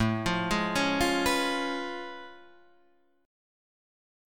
A+M9 Chord